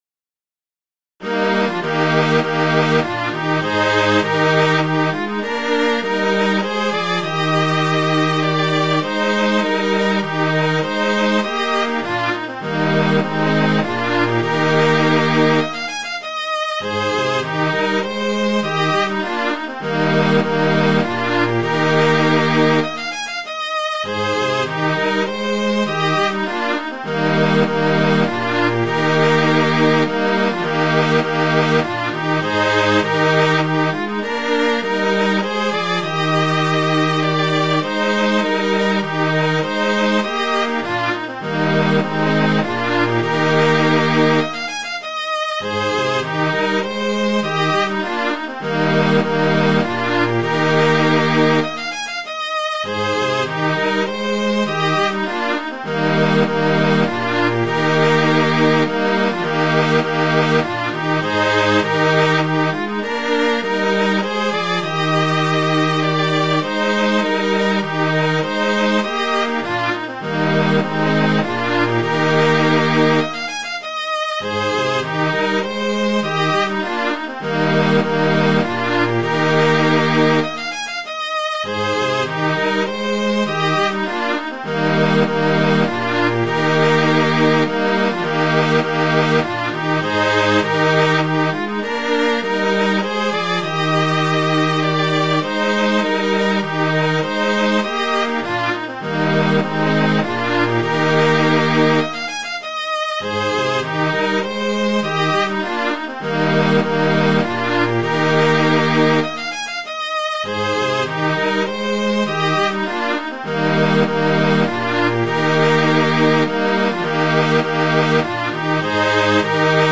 Midi File, Lyrics and Information to Gil Morice
morice.mid.ogg